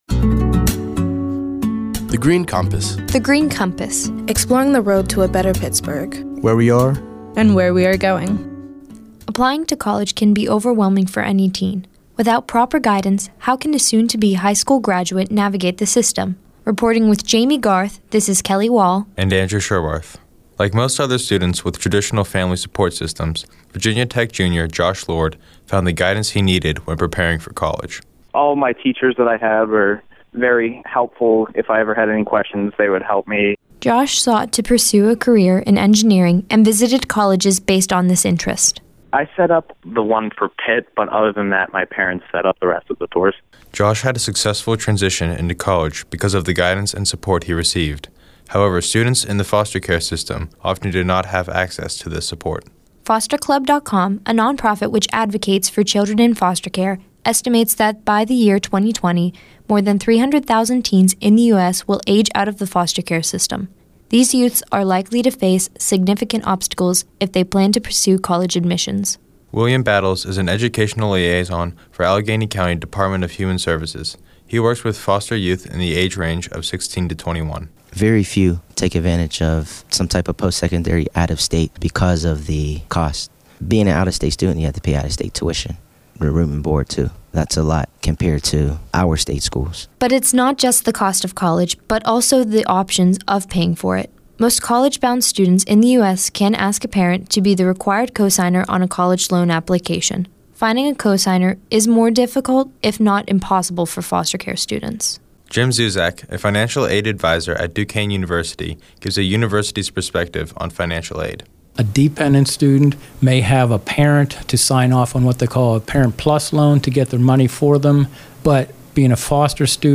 In July 2015, twenty-one recent high-school graduates created these radio features while serving as Summer Interns at The Heinz Endowments.